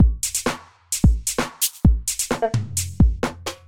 Beat Beats Drum-And-Bass Drumloops Drums Free Funk Groove sound effect free sound royalty free Music